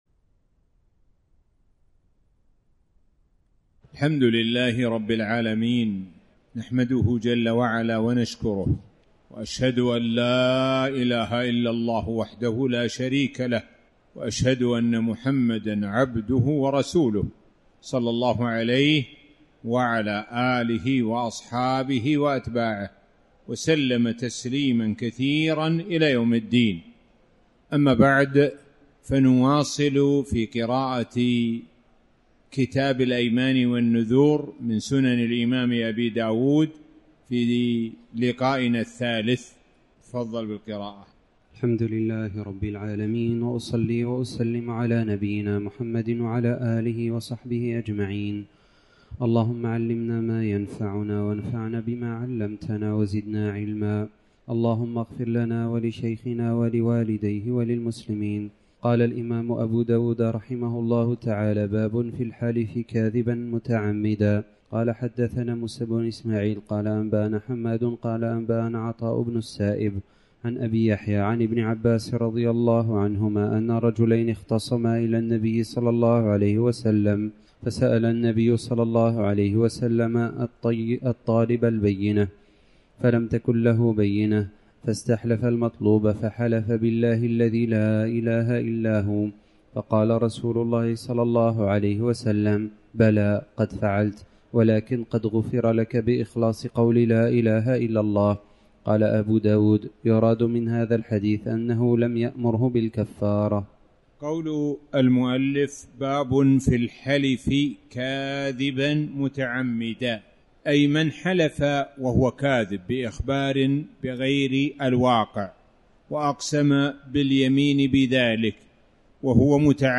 تاريخ النشر ٢٥ ذو القعدة ١٤٣٩ هـ المكان: المسجد الحرام الشيخ: معالي الشيخ د. سعد بن ناصر الشثري معالي الشيخ د. سعد بن ناصر الشثري باب في الحلف كاذباً متعمداً